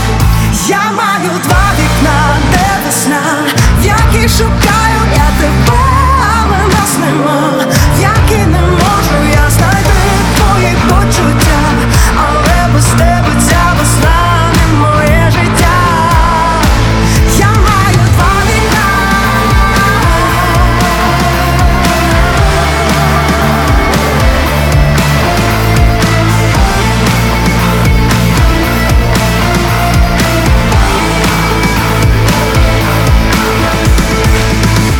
Жанр: Альтернатива / Украинские